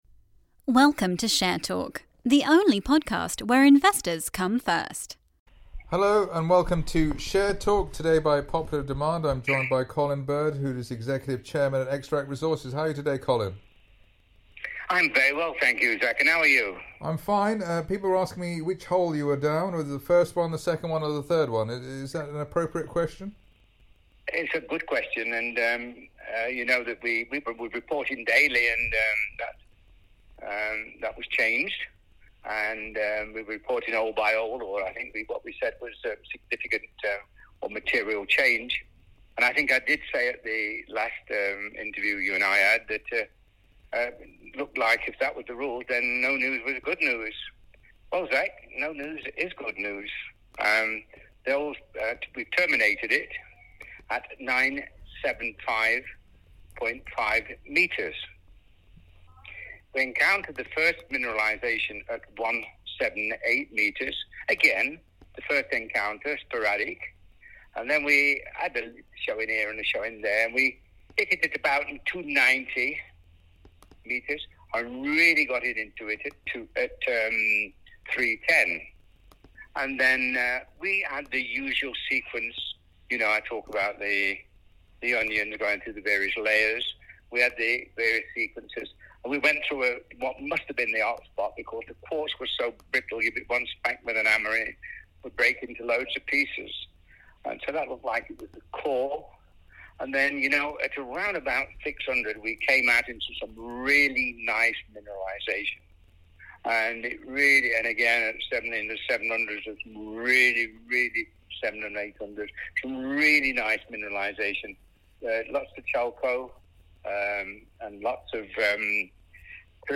Share Talk LTD / Xtract Resources Plc (XTR.L) Completion of Third Hole of Drilling Programme - Interview